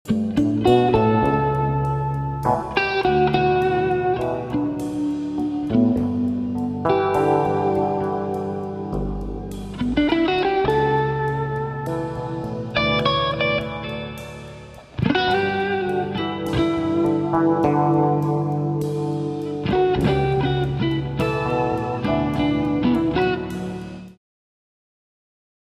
git,voc,elektrik
bass
drums